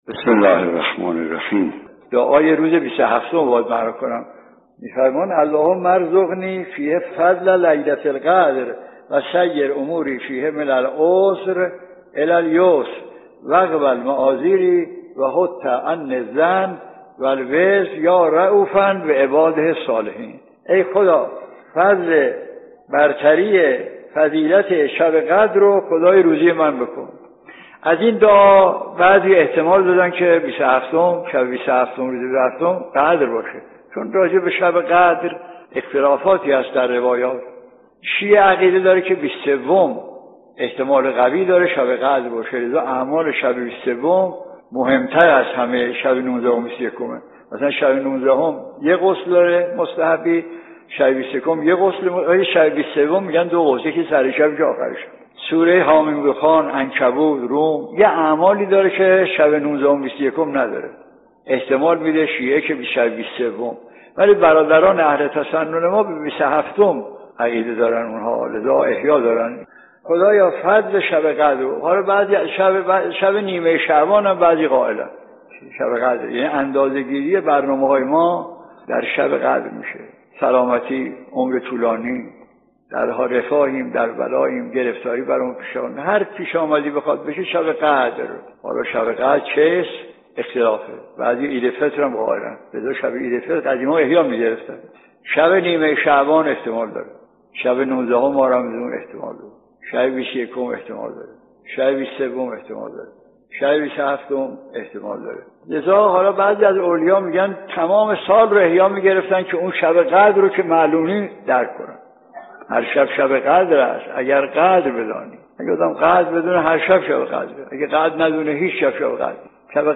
به گزارش خبرگزاری حوزه، مرحوم آیت الله مجتهدی تهرانی در یکی از سخنرانی‌های خود به «شرح دعای روز بیست و هفتم ماه مبارک رمضان» پرداختند که تقدیم شما فرهیختگان می‌شود.